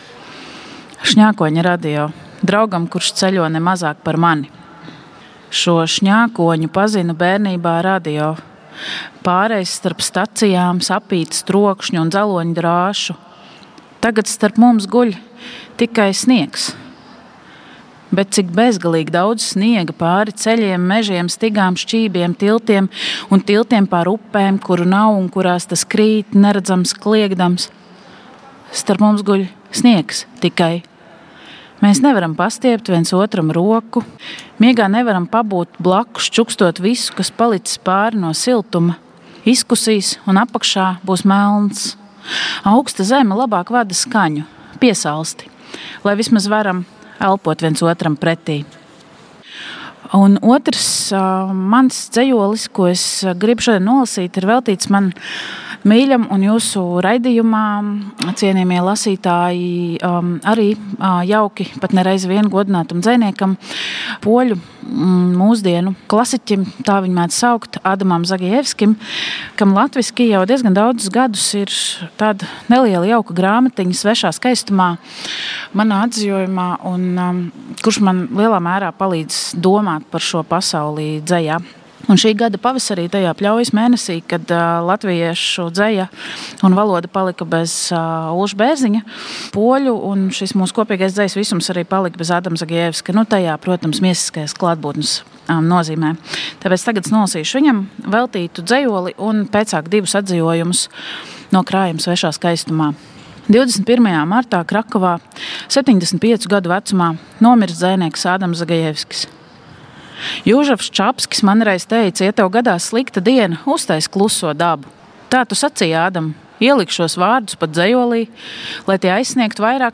Dzejas lasījumi no Latvijas Radio balkona.
Septembrī dzeja ienāk ikdienas skaņās, dzeja septembrī skan visur, tā rada savdabīgu sadzīves maģiju. Dzejas dienās aicinām ieklausīties dzejas lasījumos no Latvijas Radio balkona.